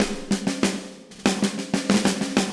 AM_MiliSnareC_95-01.wav